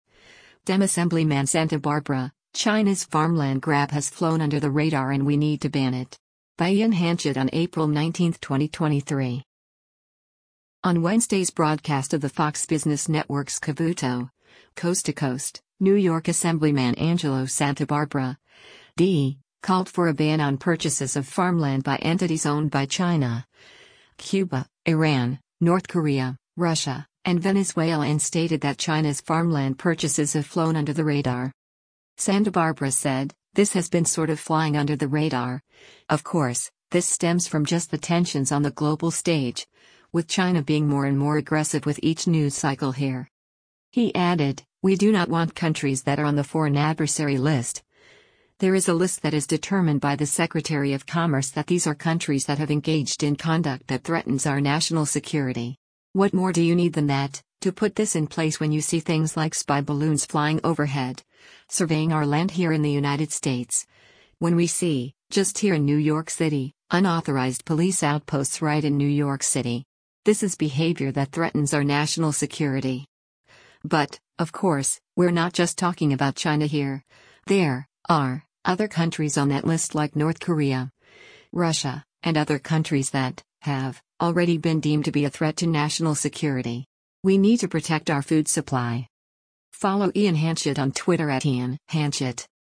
On Wednesday’s broadcast of the Fox Business Network’s “Cavuto: Coast to Coast,” New York Assemblyman Angelo Santabarbara (D) called for a ban on purchases of farmland by entities owned by China, Cuba, Iran, North Korea, Russia, and Venezuela and stated that China’s farmland purchases have flown under the radar.